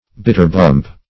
Bitterbump \Bit"ter*bump`\, n.